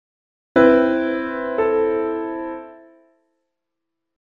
Here’s an appoggiatura:
Notice that it moves from stressed discord…
… to unstressed concord…